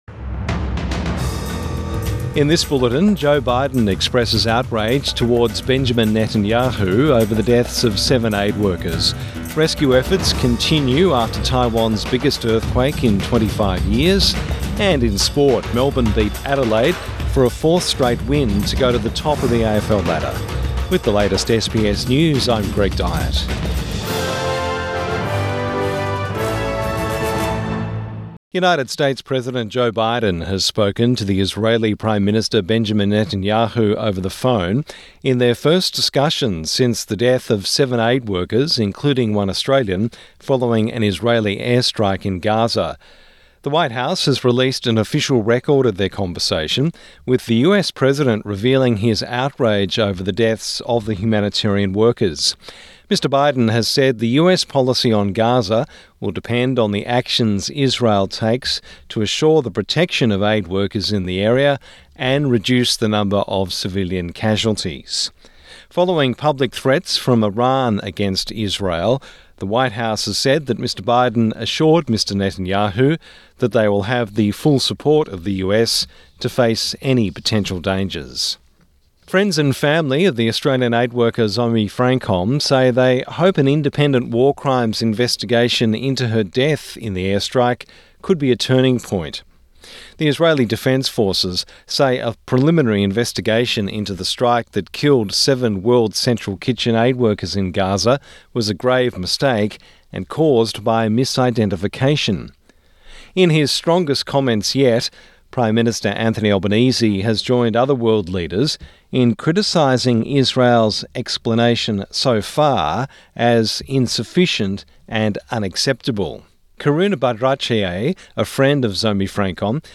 Morning News Bulletin 5 April 2024